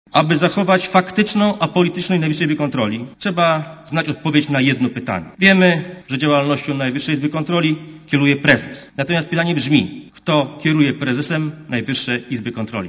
NIK?" - pytał poseł SLD Andrzej Pęczak. Opozycja, z wyjątkiem PSL,
Mówi Andrzej Pęczak (52Kb)